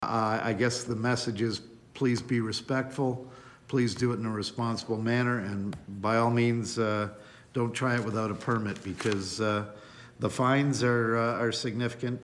At a meeting Monday councillor Chris Malette said complaints were inevitable but people should think about their neighbours and possible health effects before any burning.